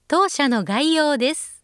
ホームページ作成で利用できる、さまざまな文章や単語を、プロナレーターがナレーション録音しています。
072-tousyanogaiyoudesu.mp3